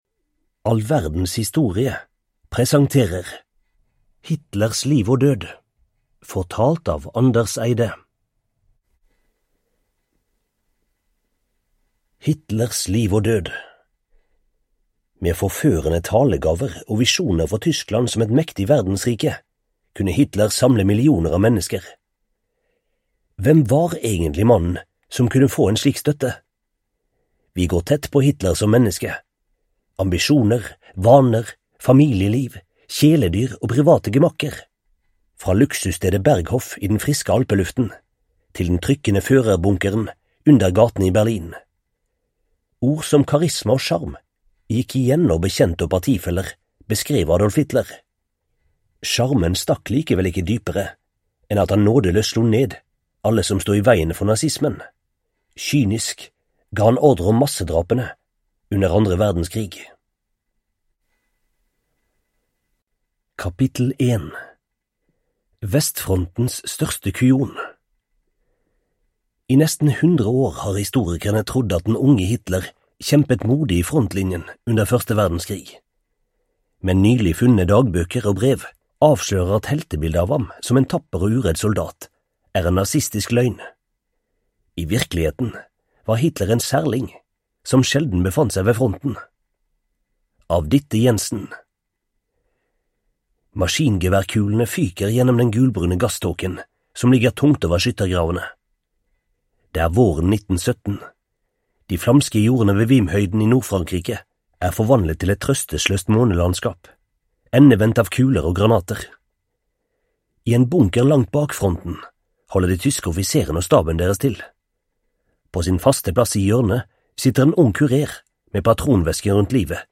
Hitlers liv og død (ljudbok) av All Verdens Historie